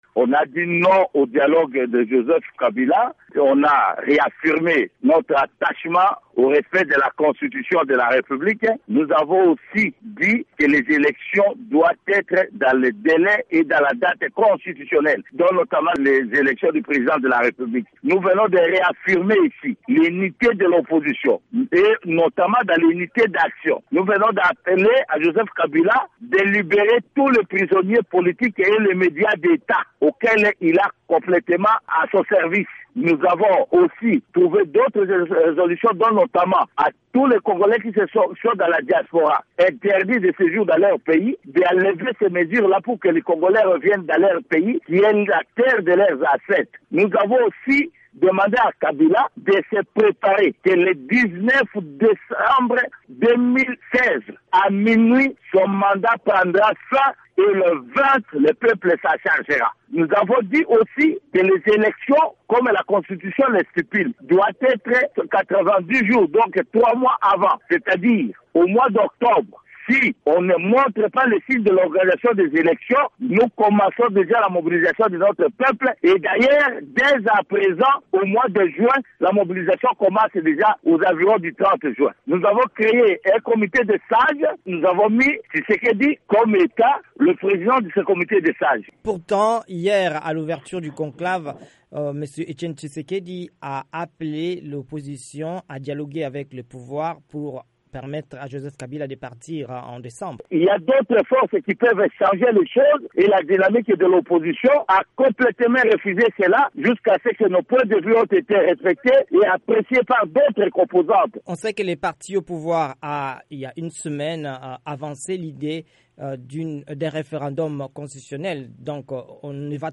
joint de Bruxelles